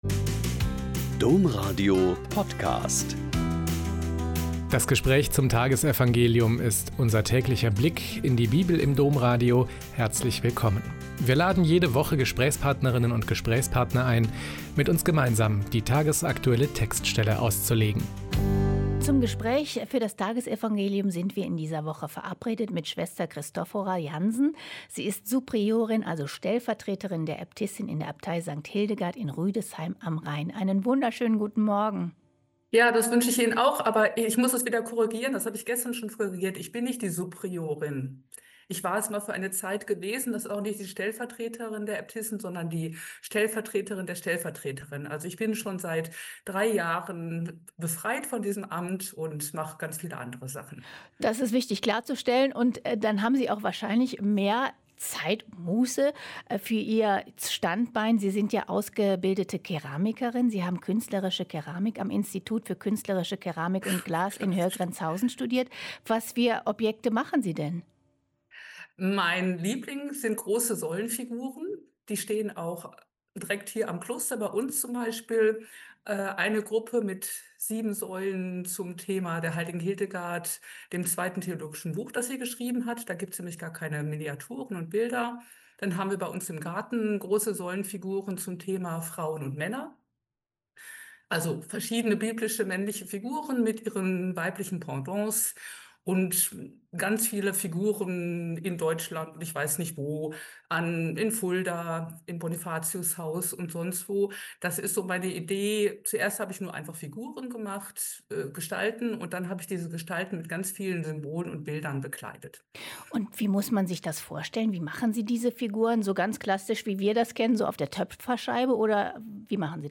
Mt 1,1-17 - Gespräch